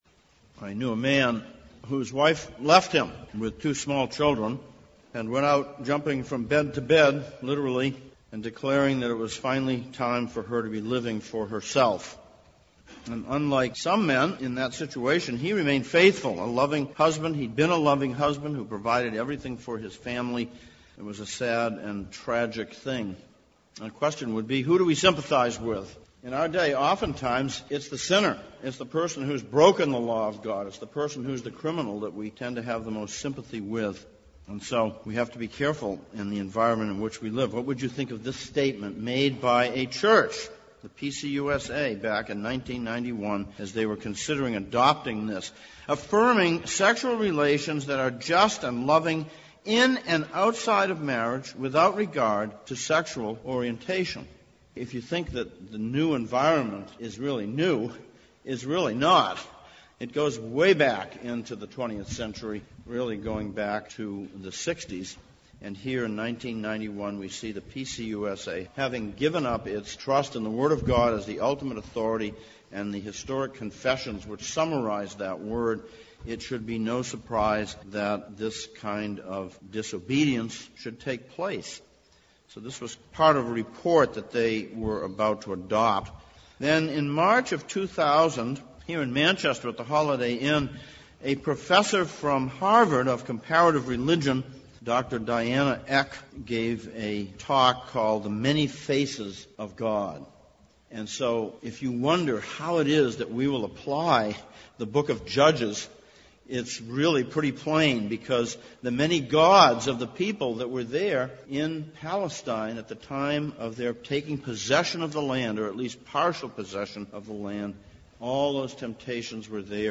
Judges Passage: Judges 2:6-3:6, 2 Corinthians 6:1-18 Service Type: Sunday Morning « Of Creation Pt 5